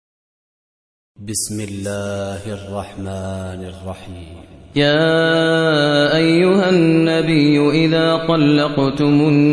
Surah Repeating تكرار السورة Download Surah حمّل السورة Reciting Murattalah Audio for 65. Surah At-Tal�q سورة الطلاق N.B *Surah Includes Al-Basmalah Reciters Sequents تتابع التلاوات Reciters Repeats تكرار التلاوات